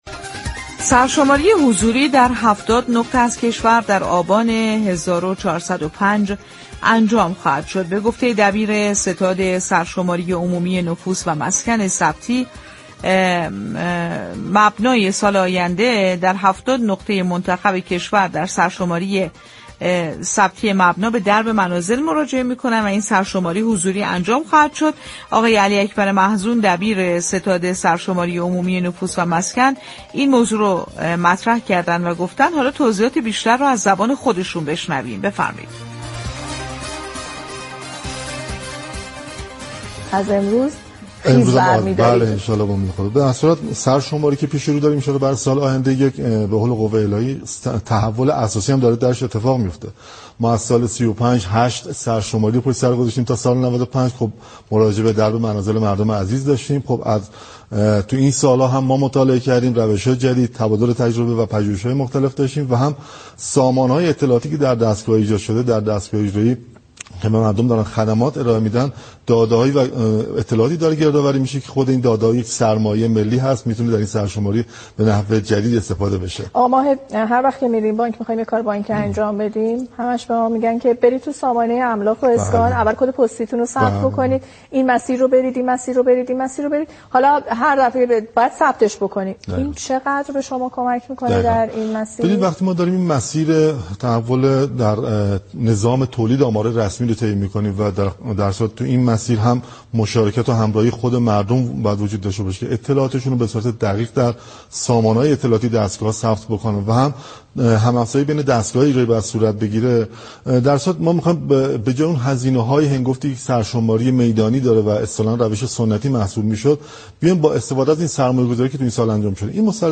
دریافت فایل به گزارش پایگاه اطلاع رسانی رادیو تهران؛ علی‌اكبر محزون، دبیر ستاد سرشماری عمومی نفوس و مسكن در برنامه «بازار تهران» با اشاره به سابقه برگزاری هشت دوره سرشماری از سال 1335 تا 1395، تأكید كرد نظام آماری كشور در آستانه یك «تحول اساسی» قرار دارد.
برنامه«بازار تهران»، روزهای شنبه تا چهارشنبه 11 تا 11:55 از رادیو تهران پخش می‌شود.